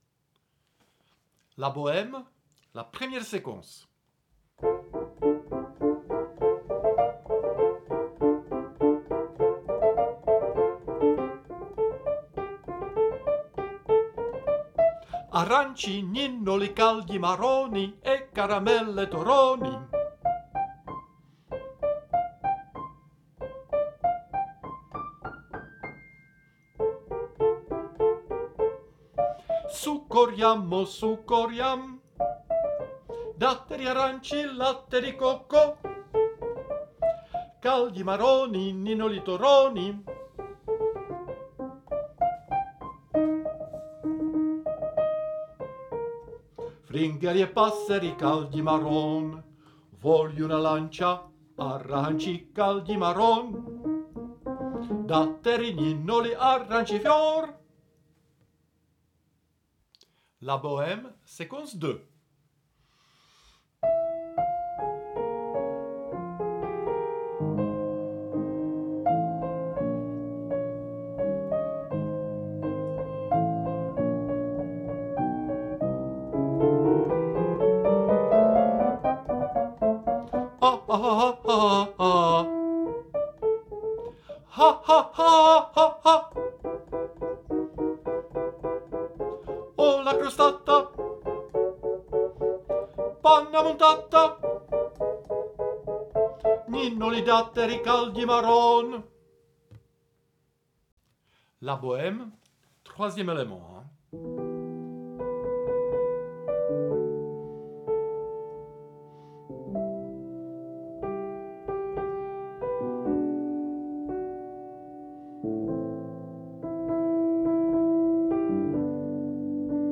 est un choeur d'enfants motivés, âgés de 11 à 15 ans, qui répète deux fois par semaine au conservatoire (une fois en partiel, lundi 18H00 - 18H45 sopranos, 18H45 - 19H30 altos, puis une fois en tutti : mercredi 15H00 - 16H30).
Enregistrement de la partie du choeur d'enfants de LA BOHEME à télécharger